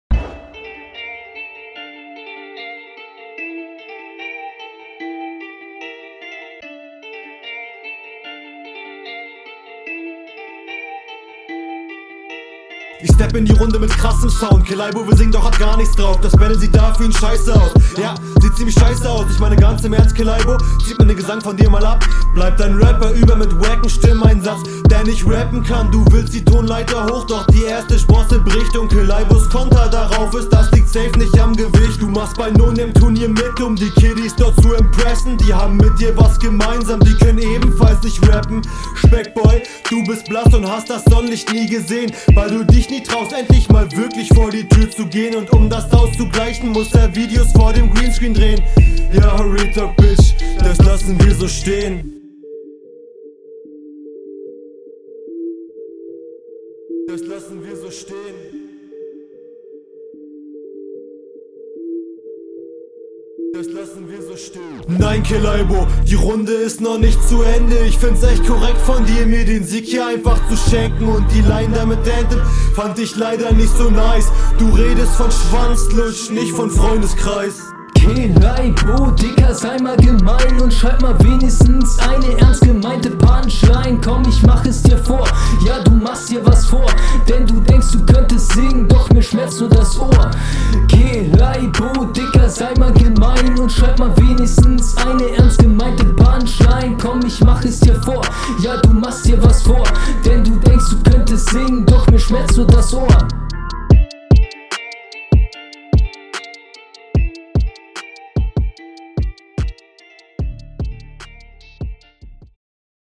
Flowlich legst du hier wieder eine Schippe drauf und steigerst dich erneut.